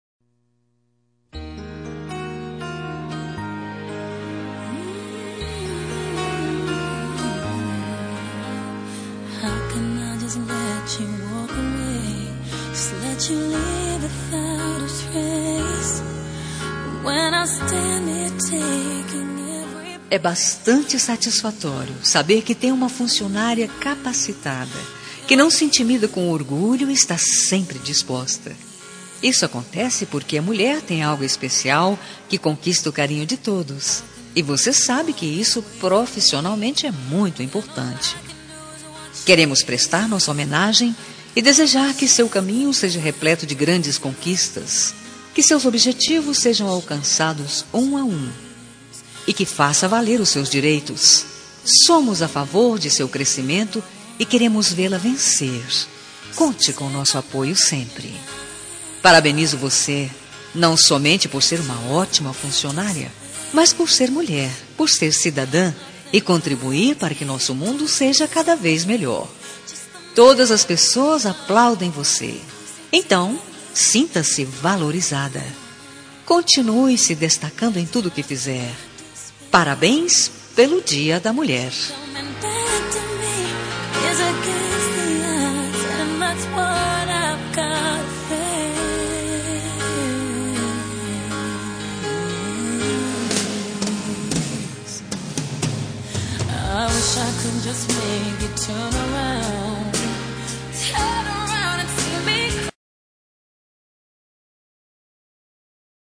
Dia das Mulheres Para Amiga – Voz Masculina – Cód: 5370